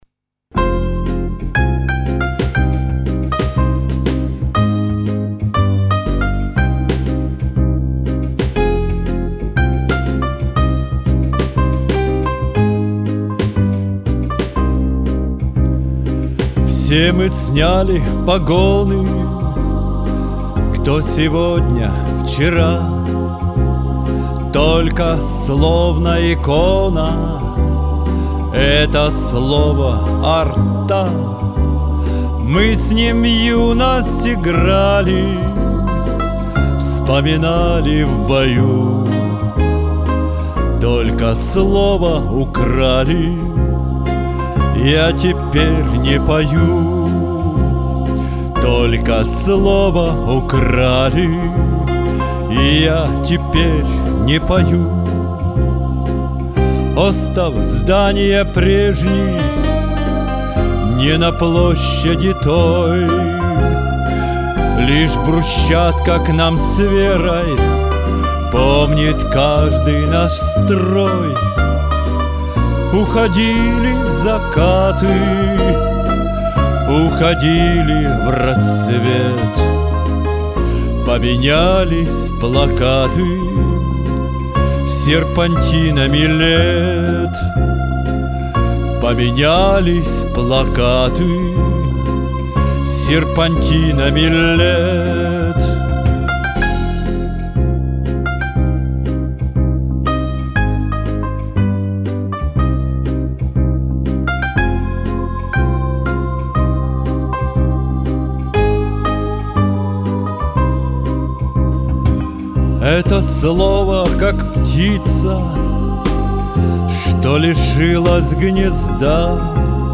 • Жанр: Военная